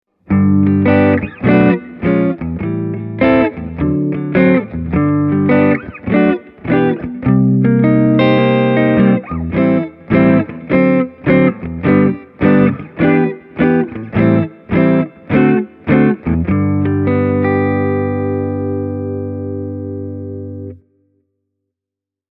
Putting good descriptions to sounds is always rather hard – so take a listen to the soundbites I recorded for you, using my Fender ’62 Telecaster Custom -reissue, as well as my Hamer USA Studio Custom. All delays and reverbs have been added at during mixdown.
Hamer – warm clean
hamer-e28093-jazz-clean.mp3